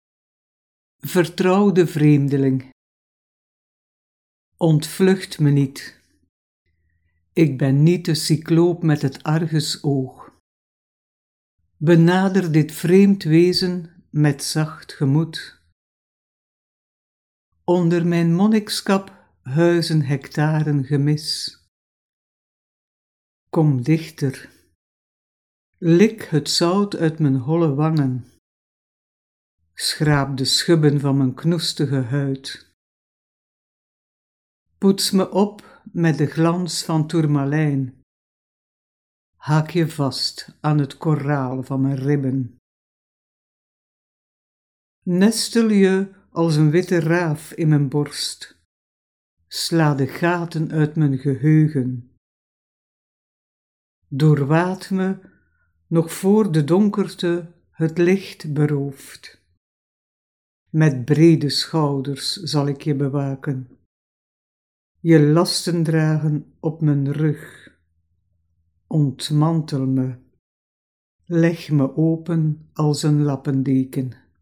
Het gedicht voorgedragen door de Poëet is te beluisteren via